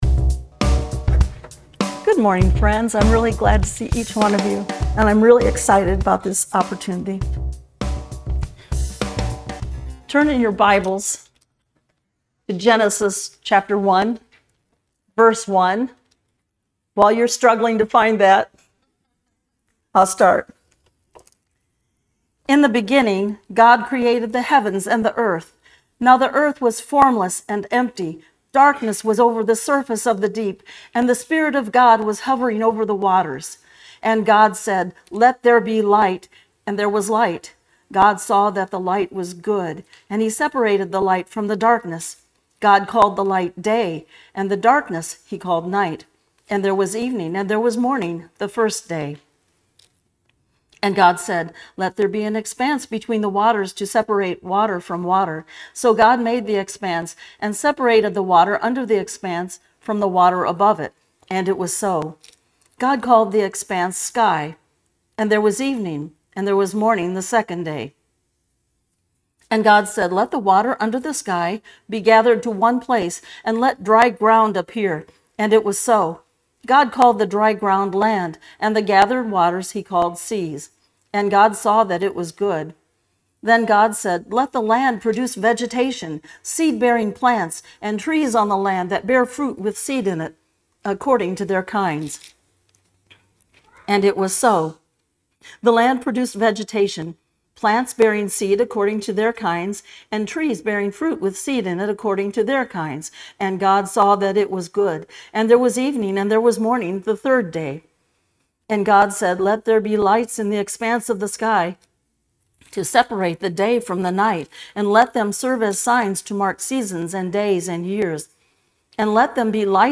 This sermon is an invitation to recognize the Creator's handiwork in the details of our lives, urging us to worship wholeheartedly beyond just singing. So, come and immerse yourself in this transformative message, and rediscover the profound connection between your daily life and the magnificent God who made you.